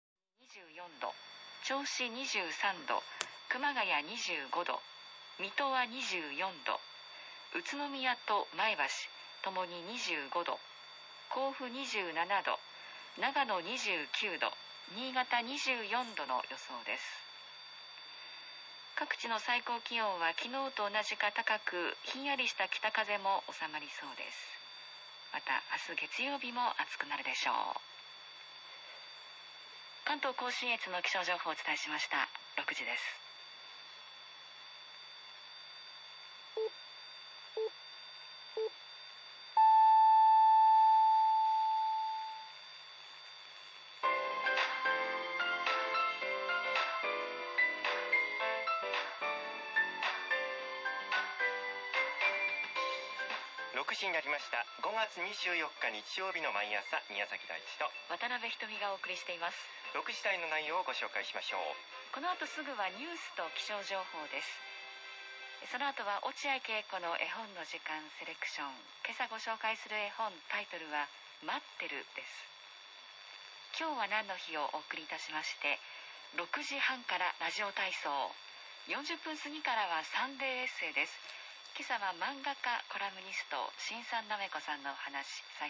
とても音の良い中波ラジオ付きの 50 MHz 4 球トランシーバーです。
中波ラジオの音